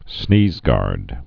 (snēzgärd)